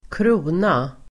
Uttal: [²kr'o:na]